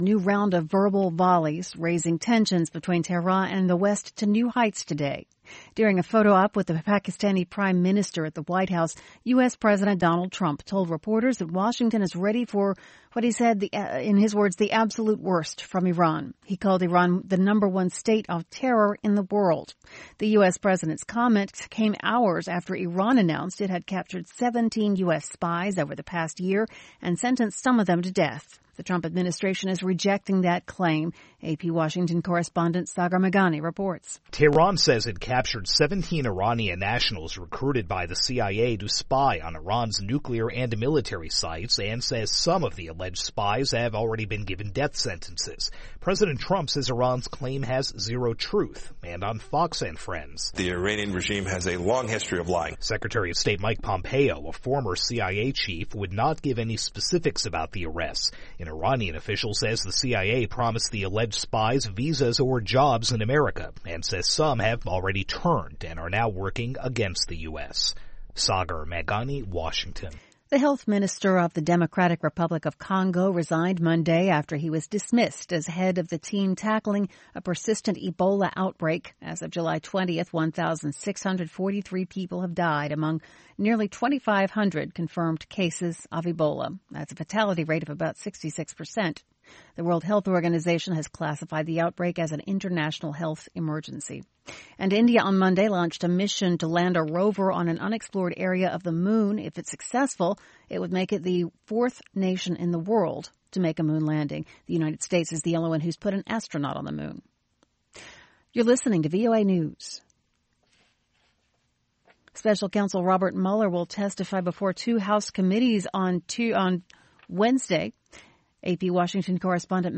VOA Newscasts (2 Minute)